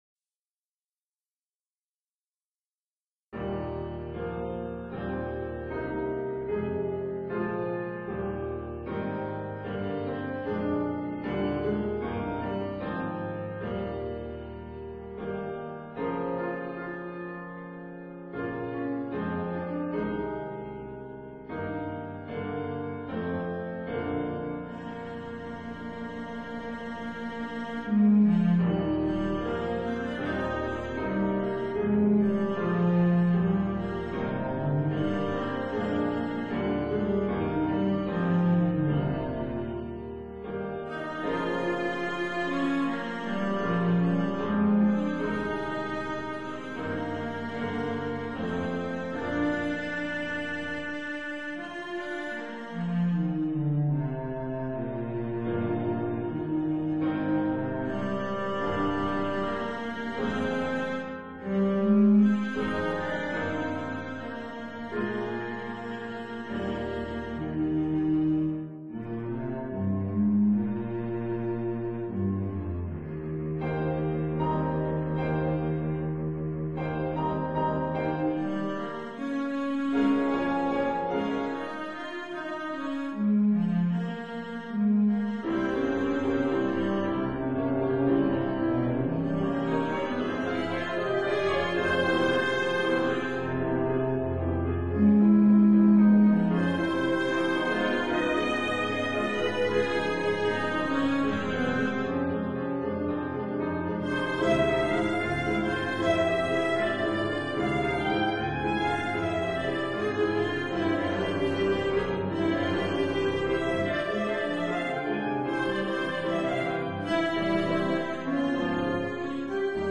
2ème mouvement (Choral) de la Sonate pour violoncelle et piano,